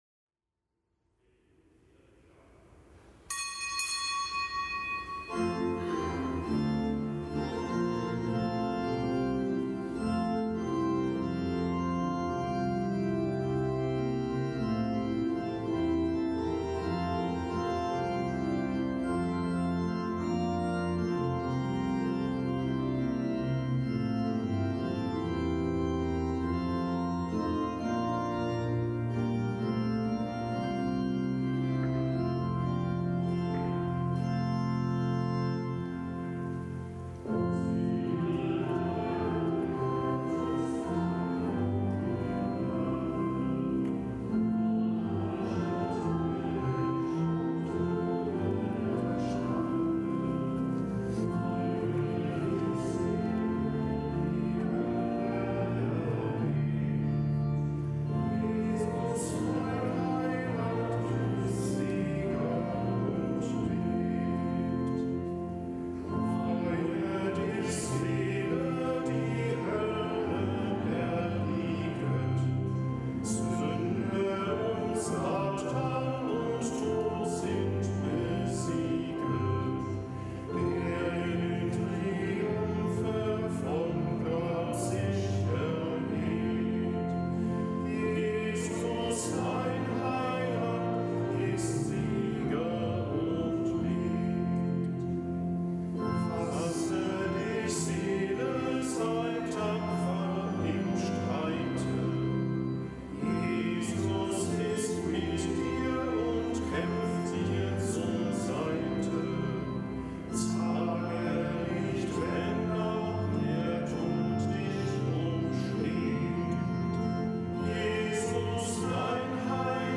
Kapitelsmesse aus dem Kölner Dom am Donnerstag der zweiten Osterwoche.